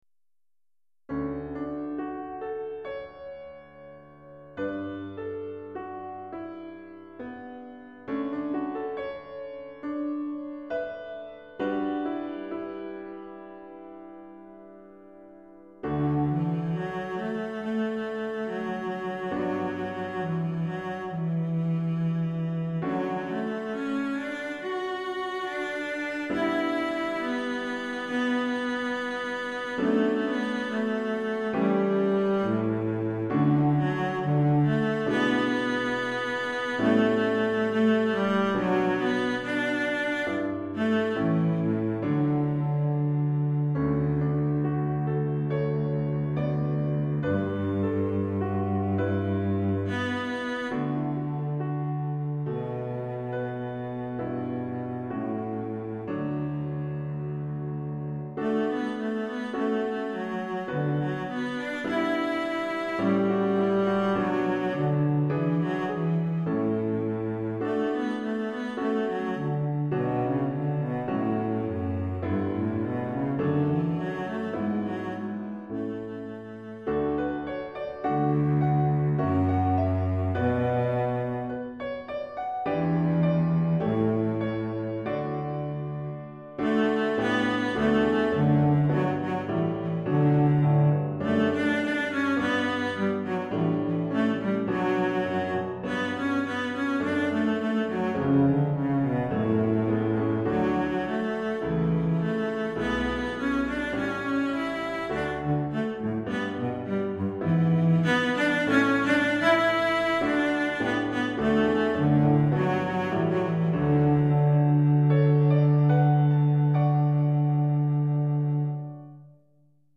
Pour violoncelle et piano DEGRE CYCLE 1
Violoncelle et piano